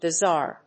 音節bi・zarre 発音記号・読み方
/bɪzάɚ(米国英語), bɪzάː(英国英語)/